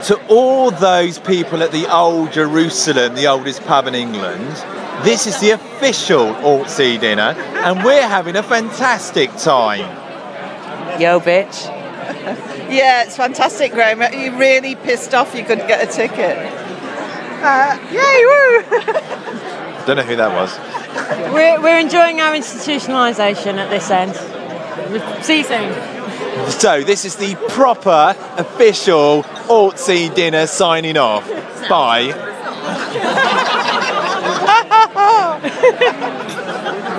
From the official dinner